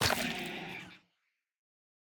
Minecraft Version Minecraft Version latest Latest Release | Latest Snapshot latest / assets / minecraft / sounds / block / sculk / break1.ogg Compare With Compare With Latest Release | Latest Snapshot
break1.ogg